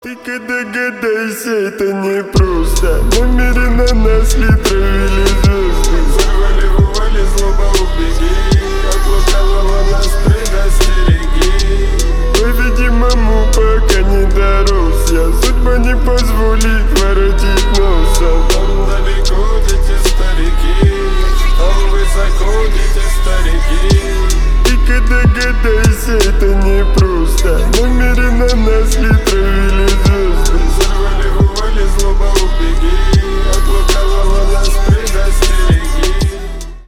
• Качество: 320 kbps, Stereo
Поп Музыка
грустные
тихие